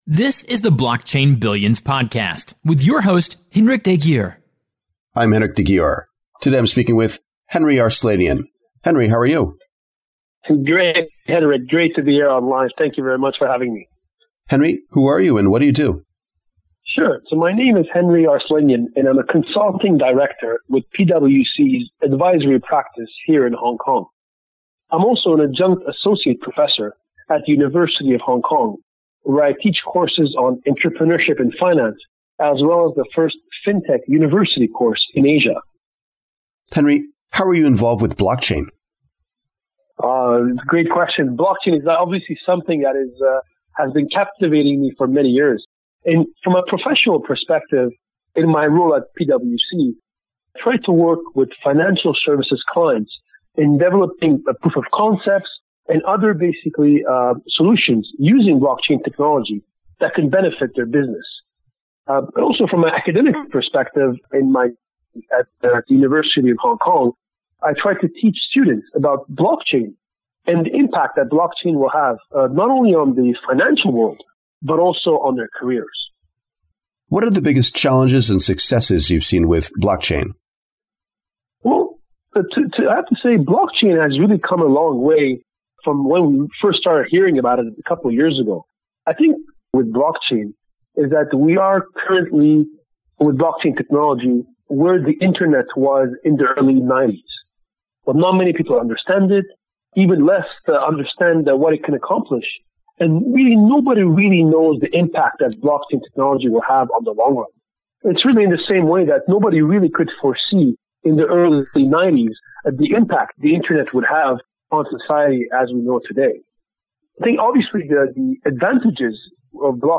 Blockchain Billions interview